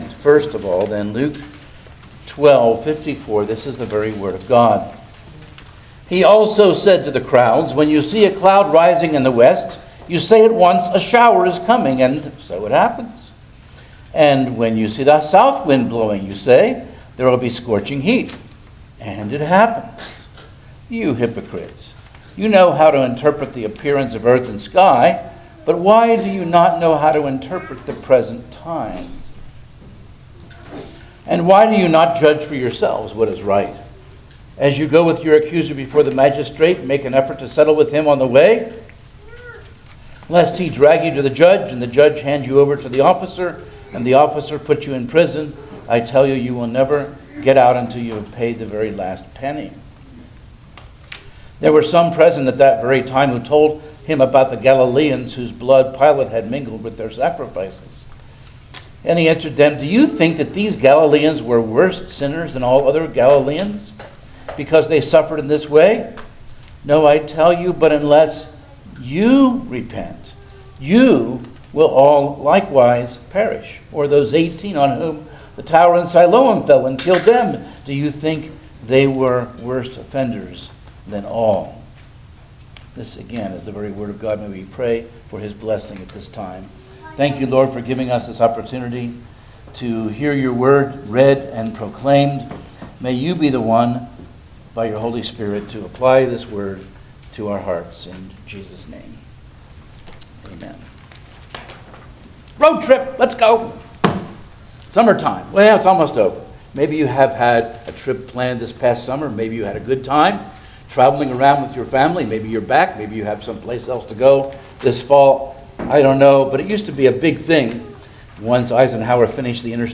Series: Guest Preachers
13:5 Service Type: Sunday Morning Service Download the order of worship here .